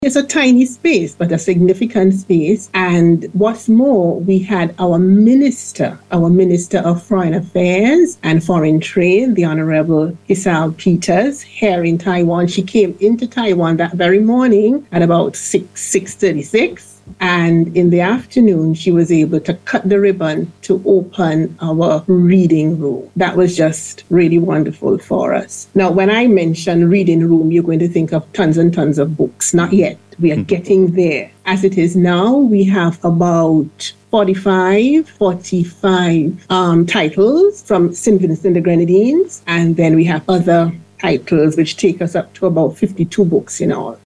Speaking on NBC Radio’s “Talk Yuh Talk” Program this morning, St. Vincent and the Grenadines’ Ambassador to the Republic of China (Taiwan) Her Excellency Andrea Bowman said she is pleased about the opening of the reading room.